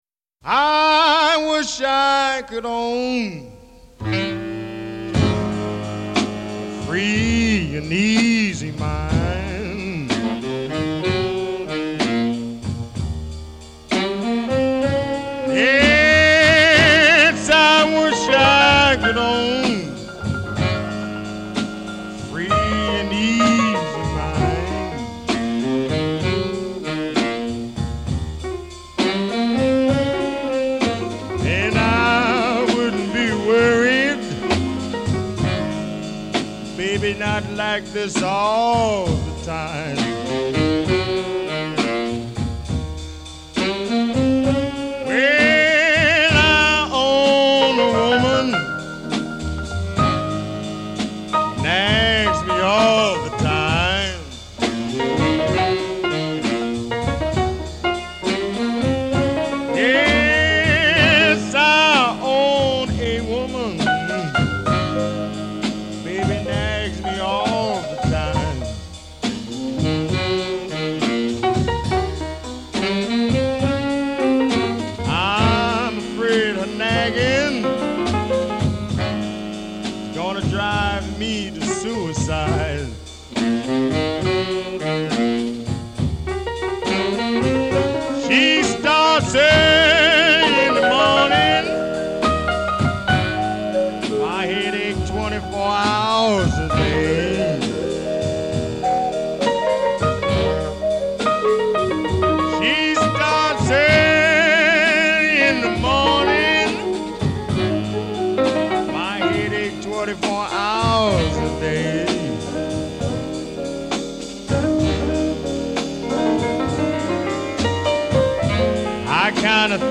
‘Big City’ blues